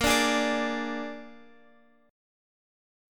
Listen to A#7 strummed